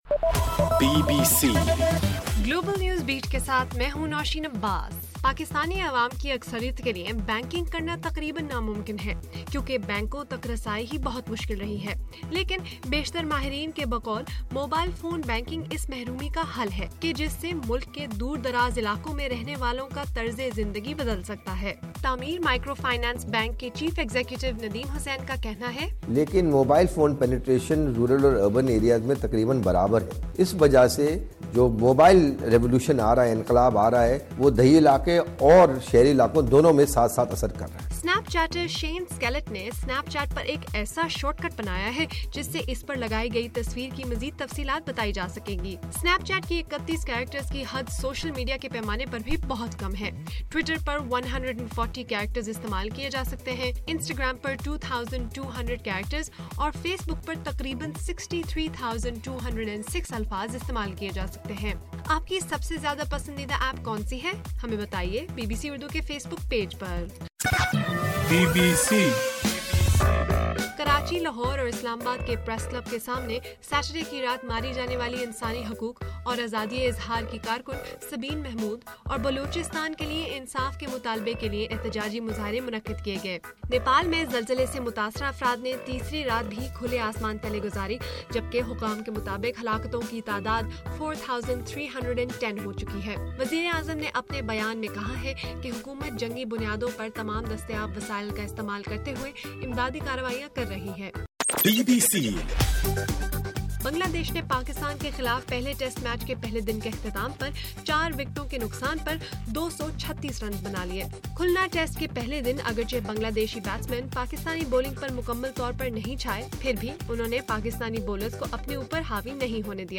اپریل 28: رات 11 بجے کا گلوبل نیوز بیٹ بُلیٹن